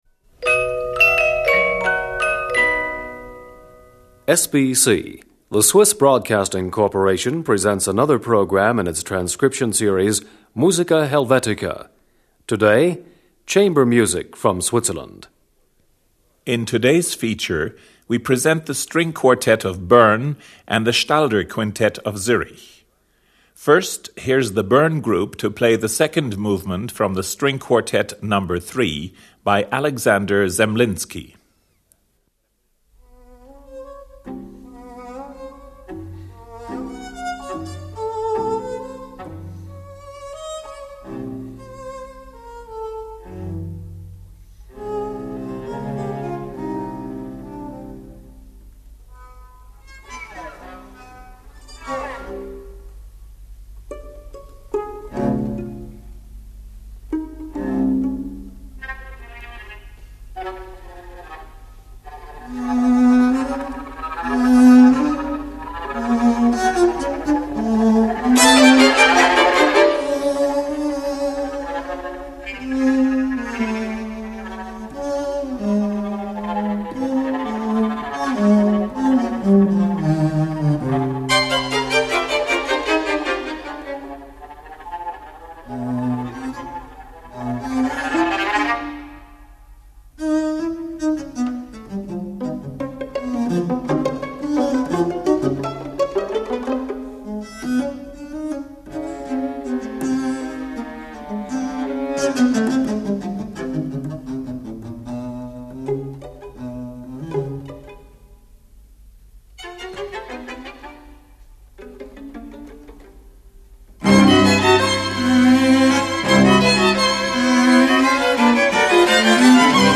violins.
viola.
cello. 2.
clarinet.
flute.
bassoon.
french horn.
oboe. 4.
For Wind Quintet.